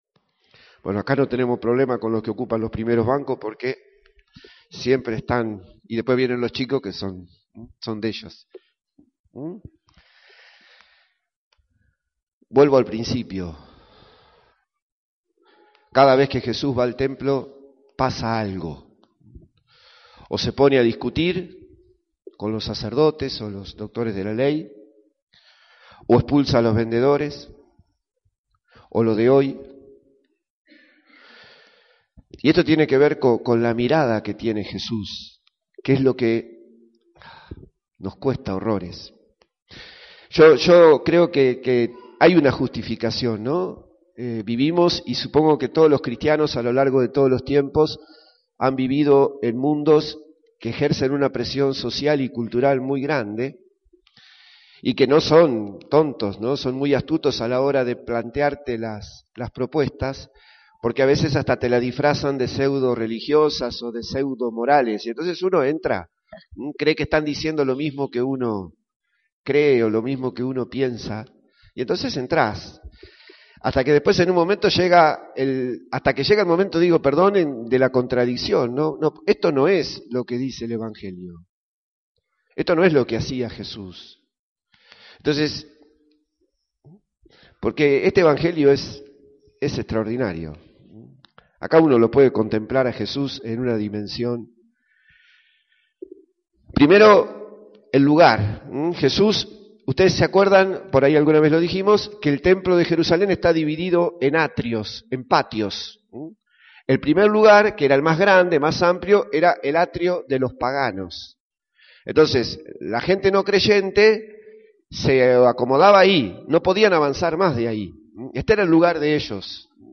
LA HOMILÍA DEL DOMINGO - Parroquia San Cayetano Chivilcoy